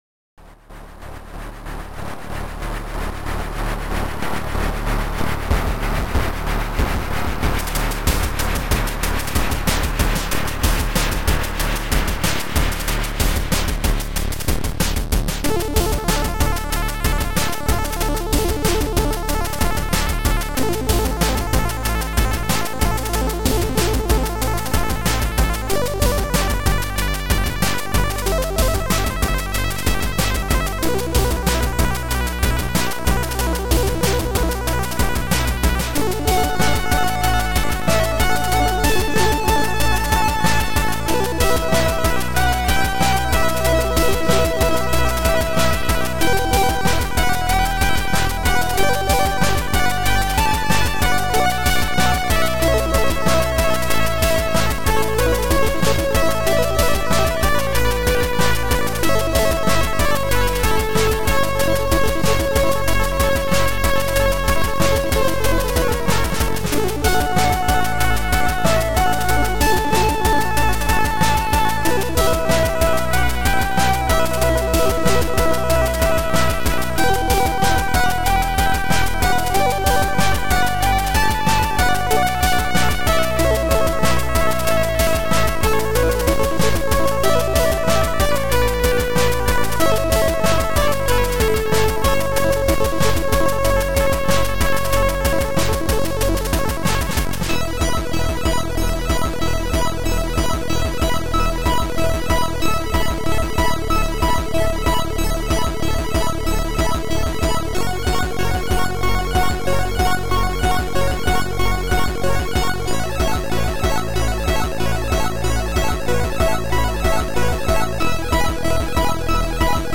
Chip Music